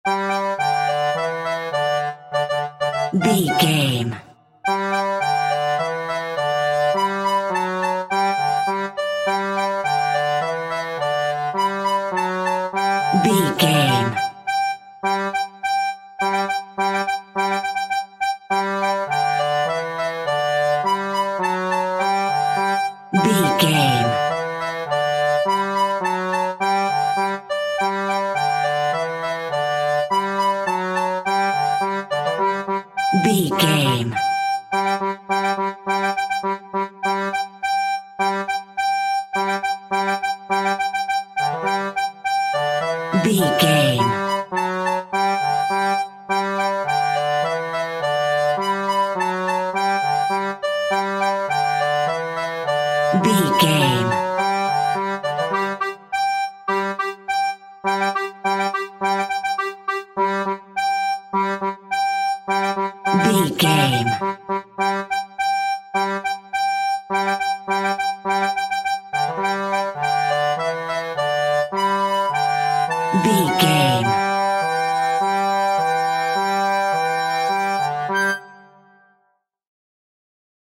Ionian/Major
nursery rhymes
kids music